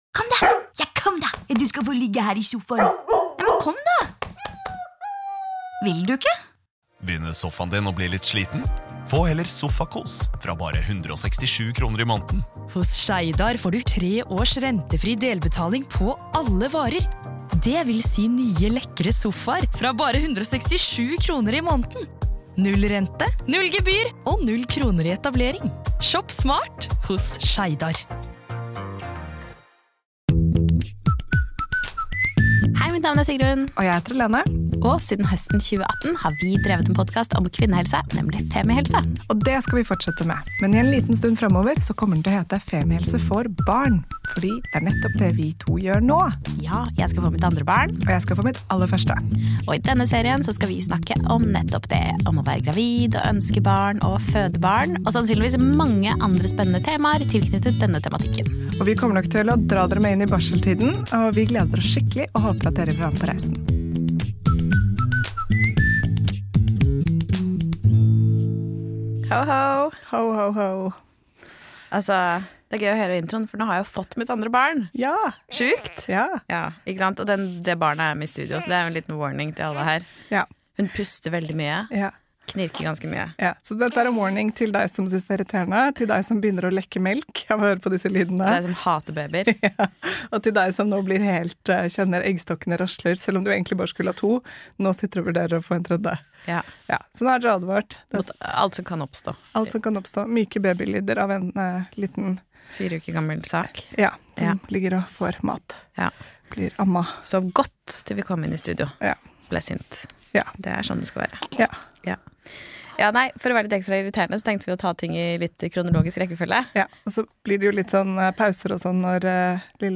Det å gå å vente på fødsel, tegn på fødsel og forsøk på på sette igang fødsel! Muligens en litt kaotisk episode da en 1 måneder gammel baby i studio også har sitt å si, så er du advart ;)…